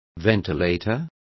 Complete with pronunciation of the translation of ventilator.